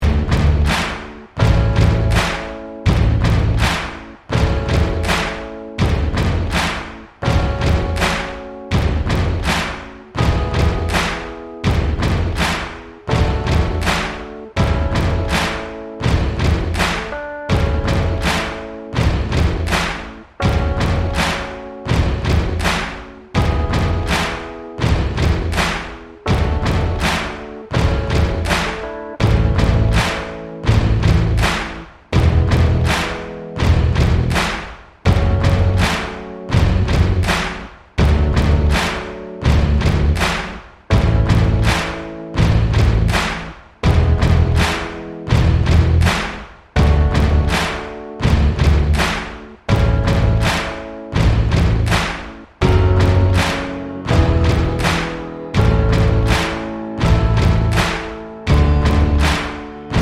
no Backing Vocals Mashups 3:10 Buy £1.50